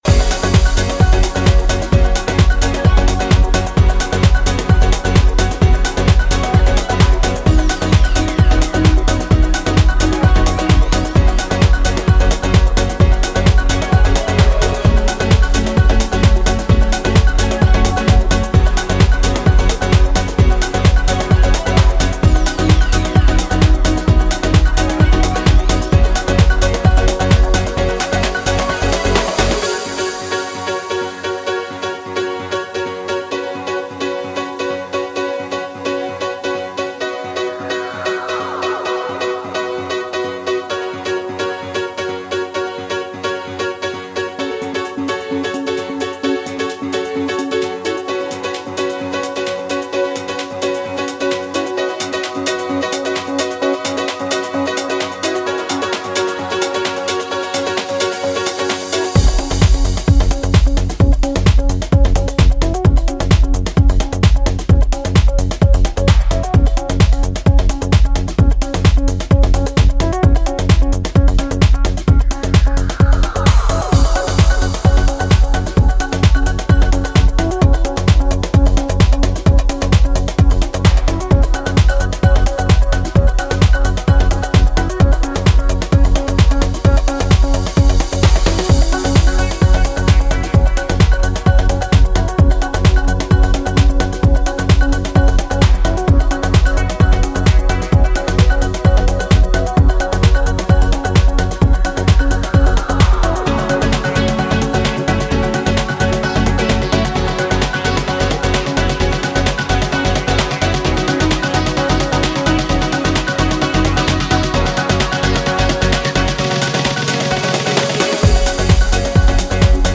トランシーな上昇気流が楽園的ピアノリフで昇天する